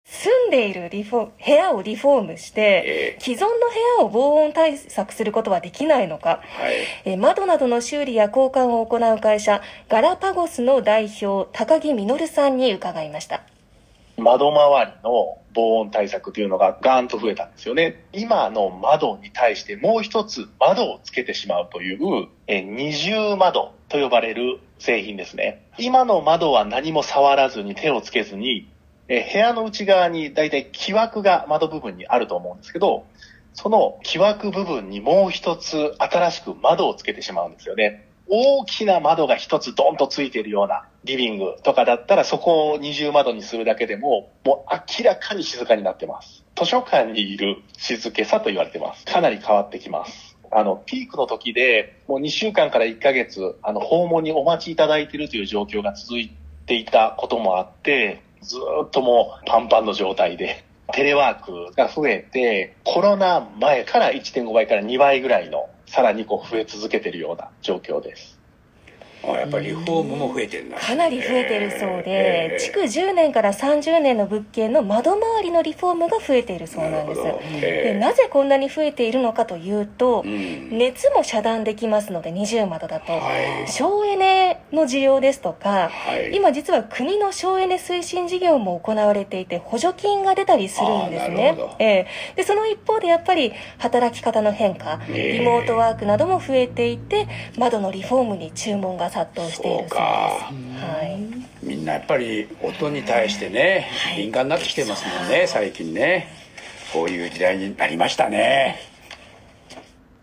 ※2023年10月26日放送　TBSラジオ「森本毅郎・スタンバイ！」より音声抜粋「窓の防音リフォームについて」